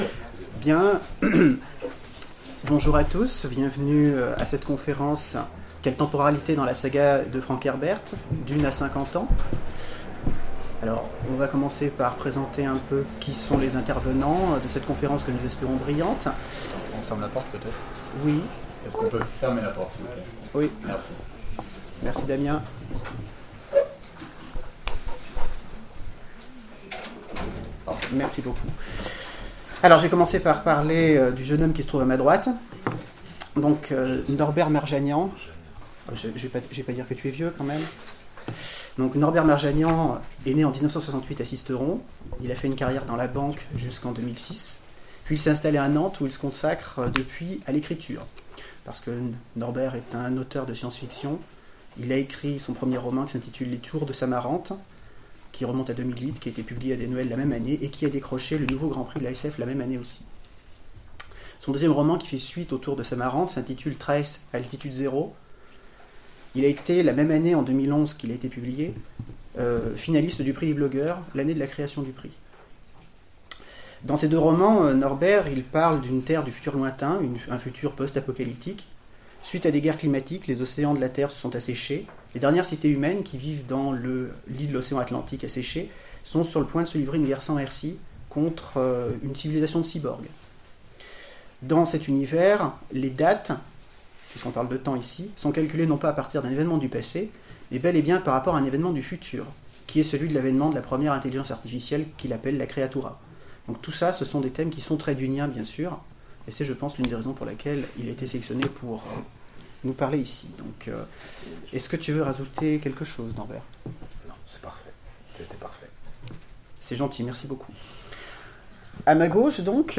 Intergalactiques 2015 : Conférence DUNE à 50 ans